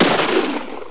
shoot.mp3